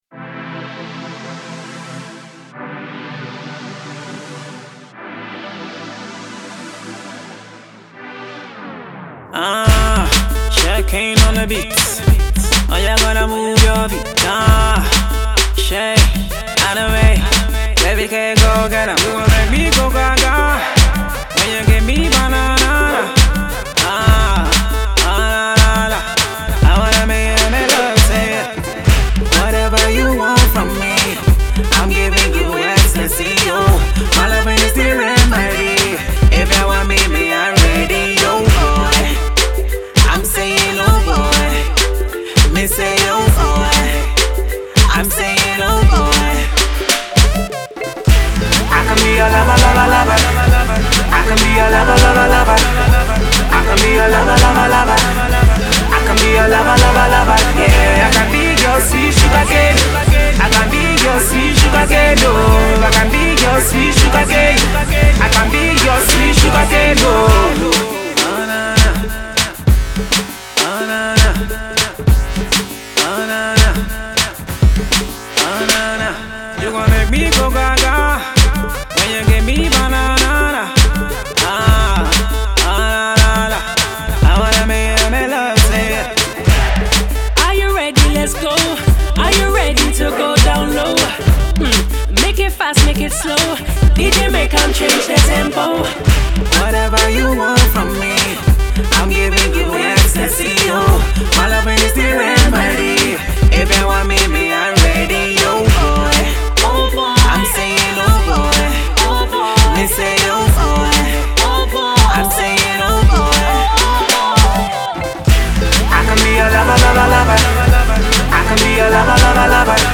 Girl pop group
they are back with a club banger